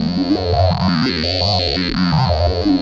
Index of /musicradar/rhythmic-inspiration-samples/85bpm
RI_ArpegiFex_85-01.wav